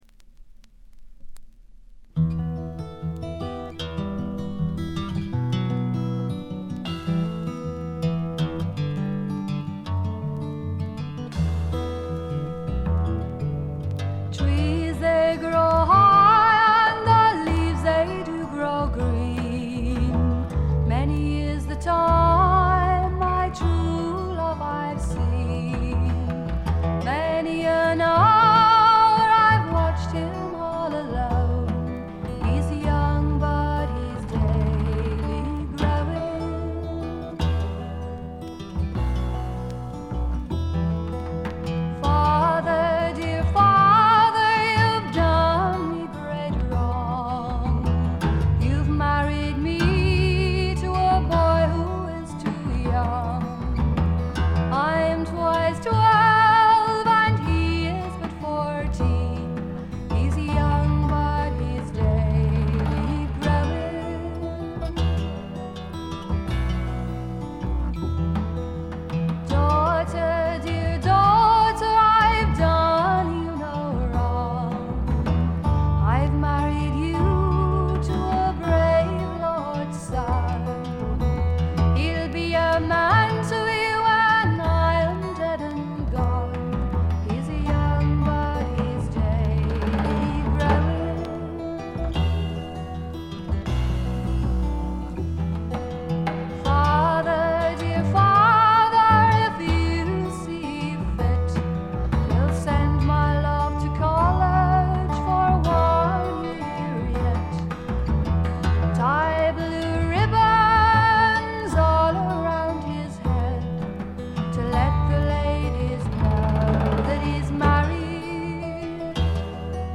試聴曲は現品からの取り込み音源です。
Sides 3 and 4, Recorded at IBC Studios,London, August 1968.